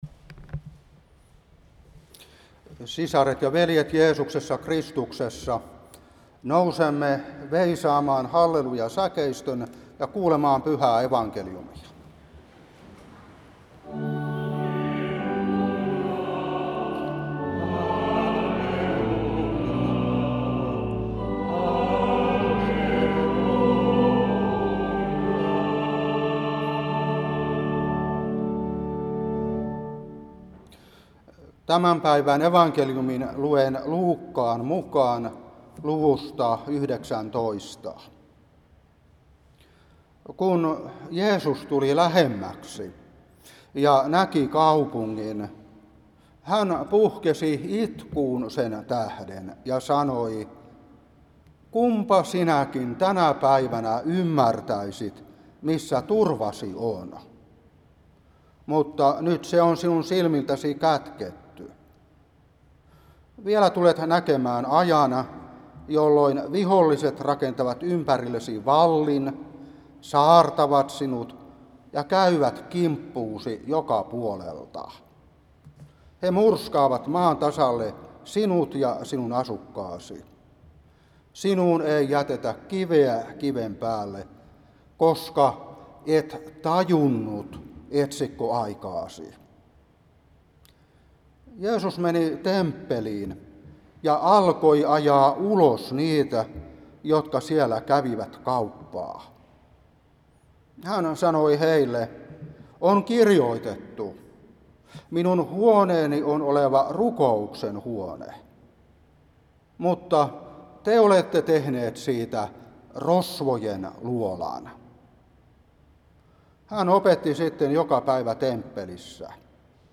Saarna 2025-8.